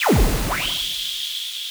RI_RhythNoise_140-03.wav